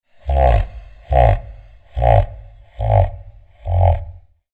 Deep Slow Cartoon Laugh Sound Effect
Description: Deep slow cartoon laugh sound effect. Deep male voice laughing in extreme slow motion. Comedy spooky laughing sound.
Deep-slow-cartoon-laugh-sound-effect.mp3